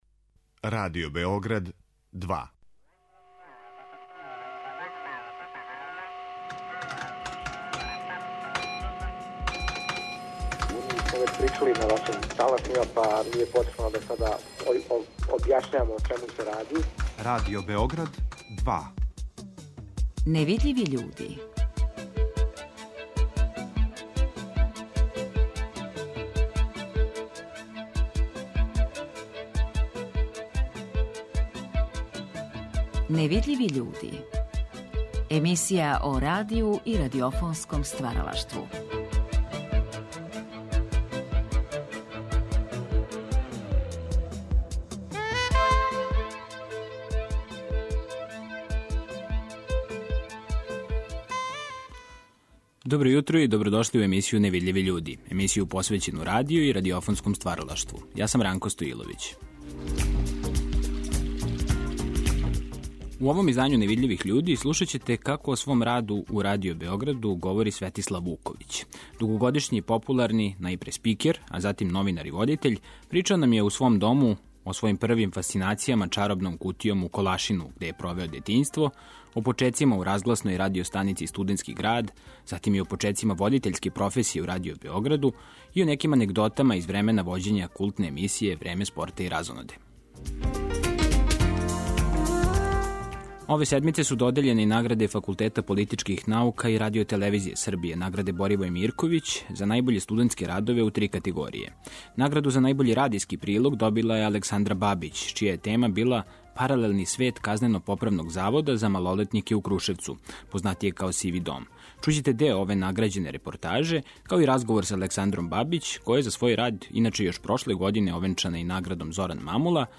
причао нам је у свом дому